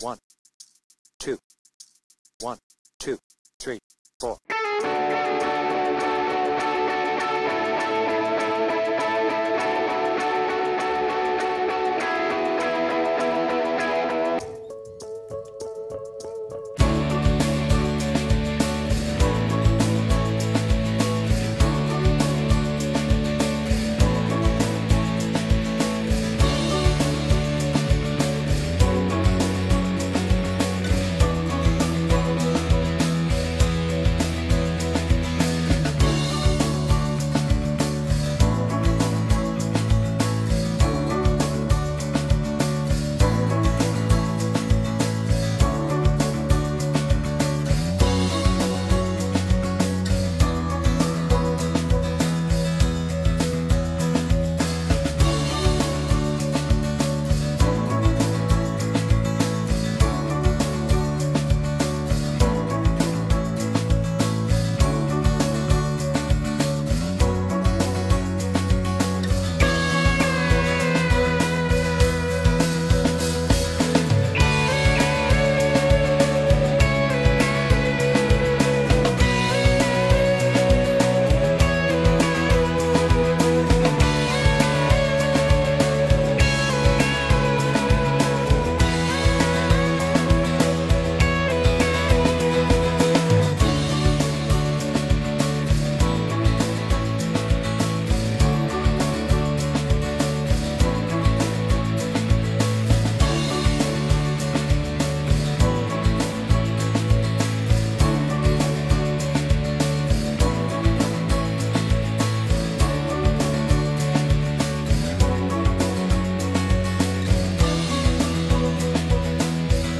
Tuning : E
Without vocals